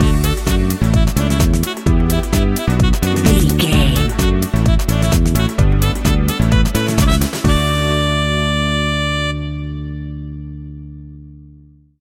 Aeolian/Minor
fun
groovy
uplifting
driving
energetic
bass guitar
drums
strings
saxophone
piano
electric piano
deep house
nu disco
synth
upbeat